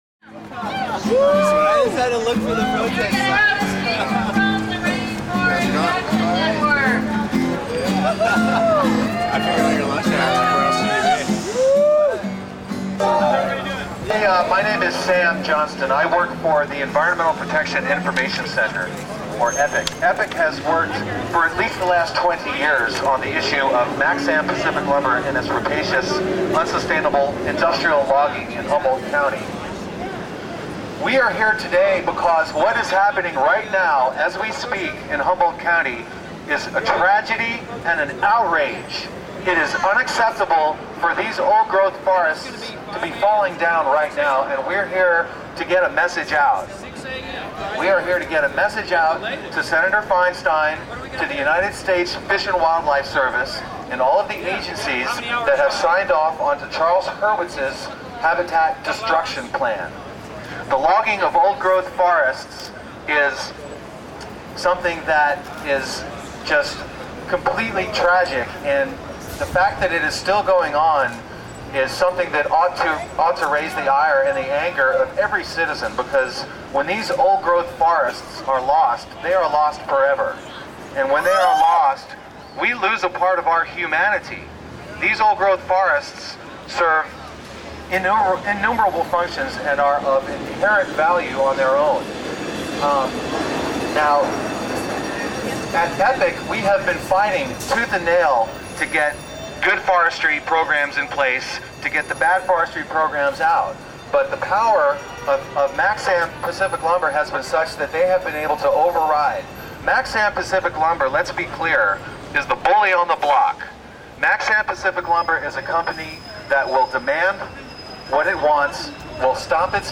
Rally to Protect Ancient Redwoods : Indybay
Environmentalists from groups including Bay Area Coalition for Headwaters, Earth First!, Humboldt Forest Defense, EPIC, We Save Trees and R.A.N. assembled in front of Senator Feinsteins's downtown office to protest against the logging of Ancient Redwoods in the Nanning Creek Grove.